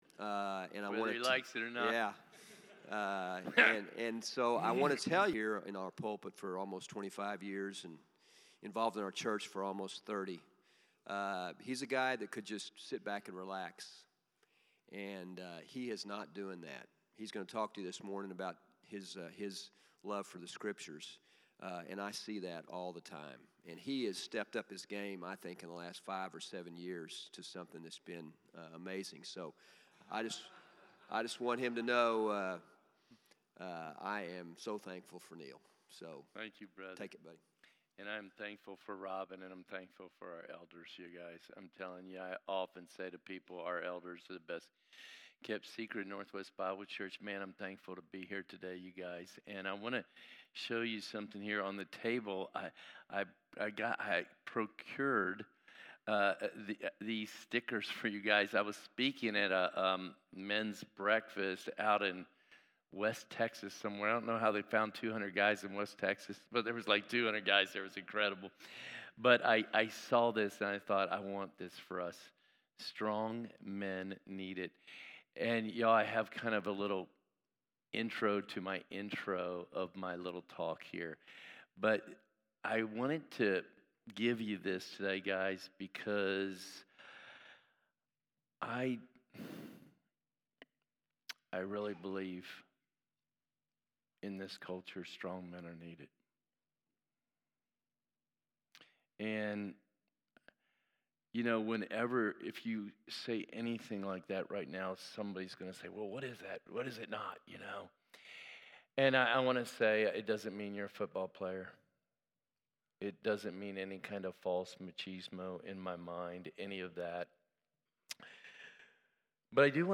Men’s Breakfast